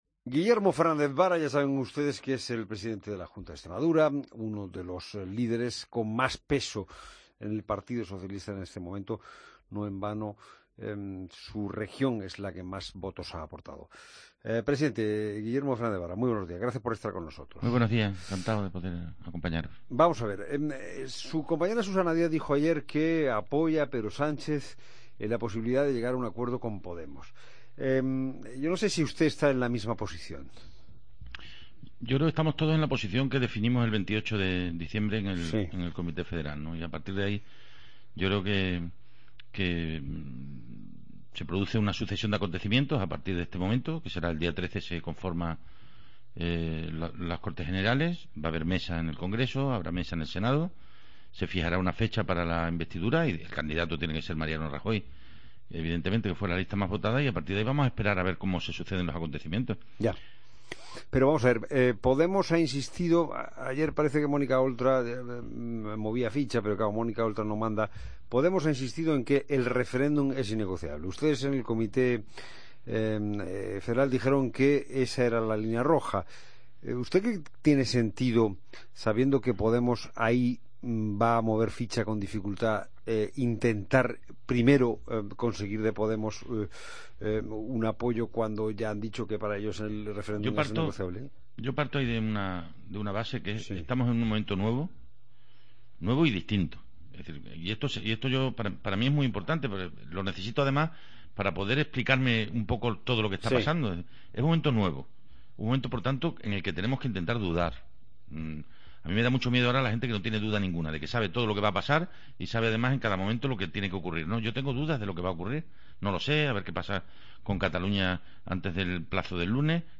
AUDIO: Escucha la entrevista a Guillermo Fernández Vara, Presidente de Extramadura, en La Mañana del Fin de Semana.